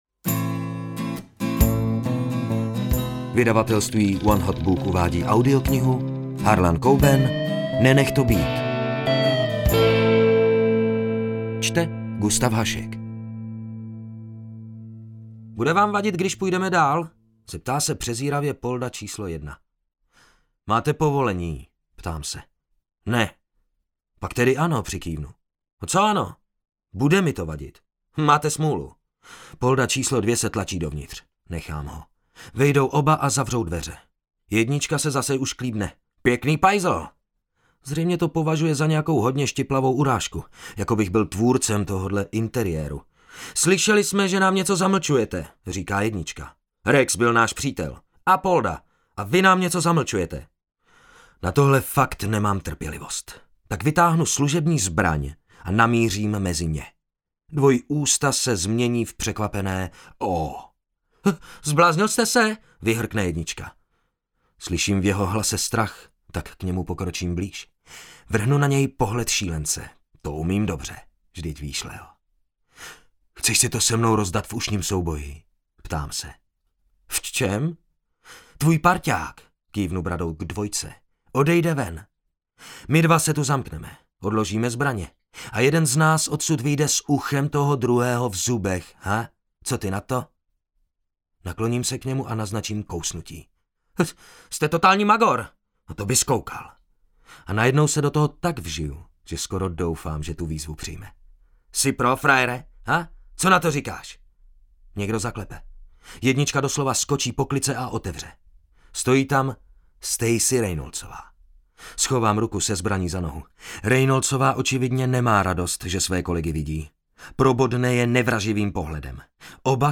Nenech to být audiokniha
Ukázka z knihy